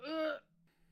argh.ogg